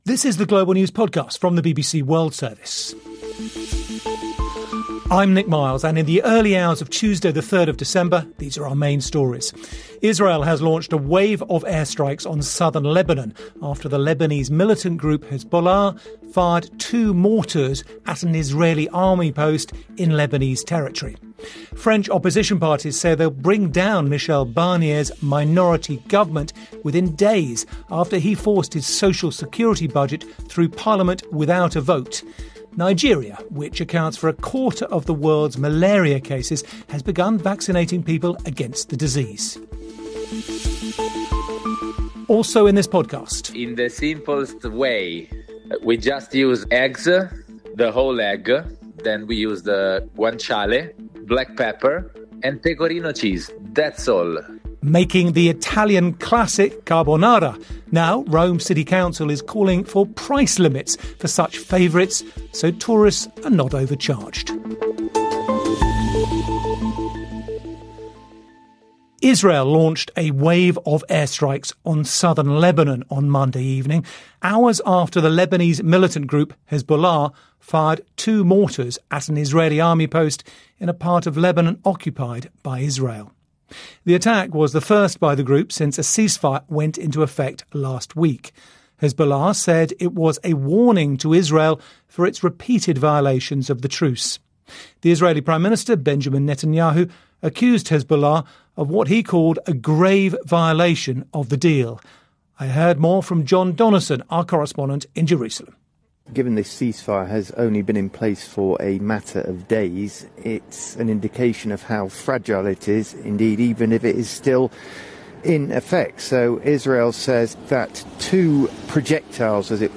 The best stories, interviews and on the spot reporting from around the world including highlights from News hour, The World Today and World Briefing.